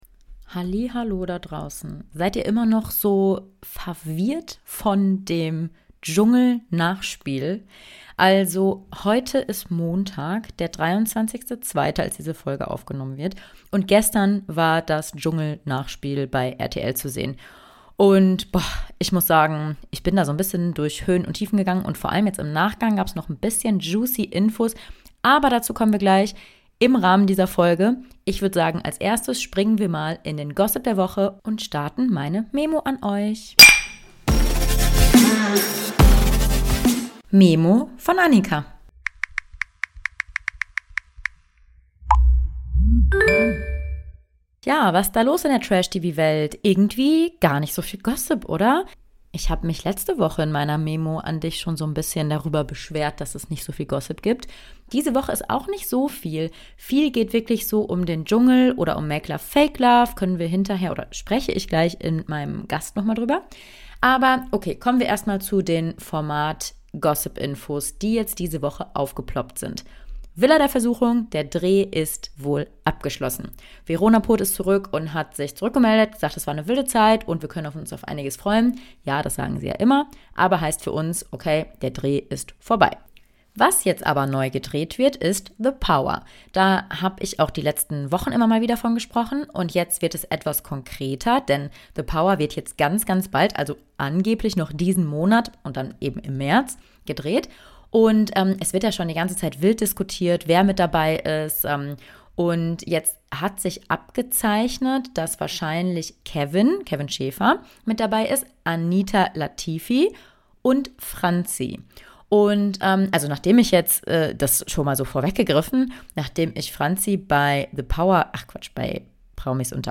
In dieser Podcast-Folge spreche ich mit meinem Gast deshalb natürlich über das Dschungel Nachspiel.